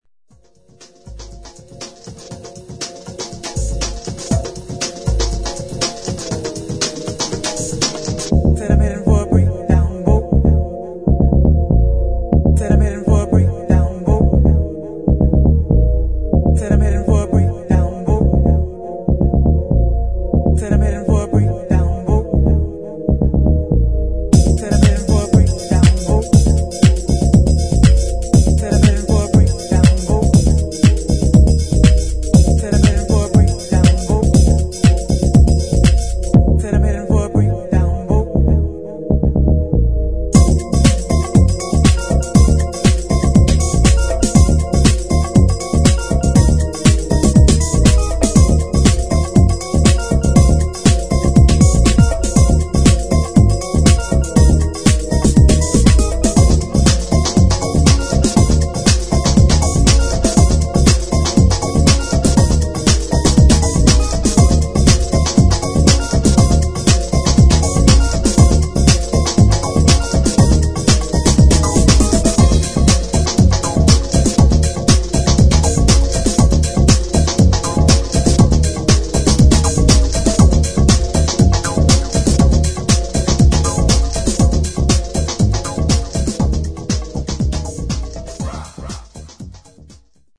[ HOUSE / BREAKBEAT ]